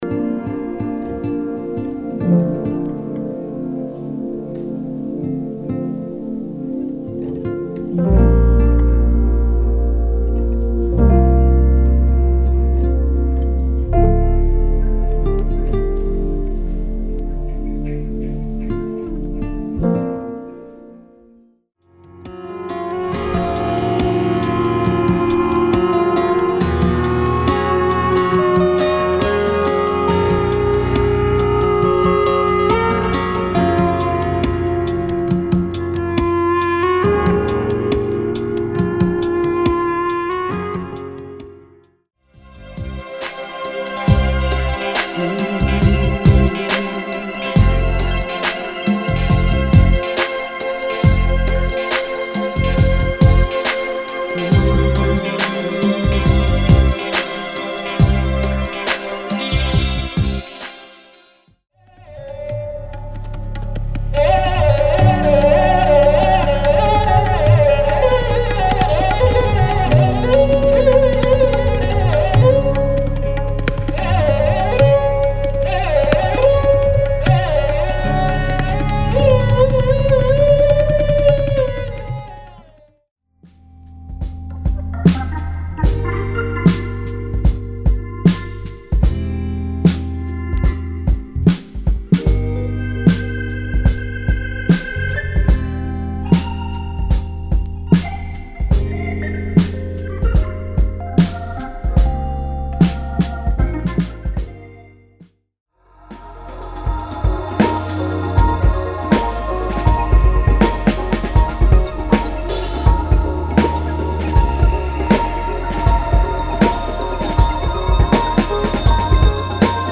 〈試聴〉 ダイジェストになります。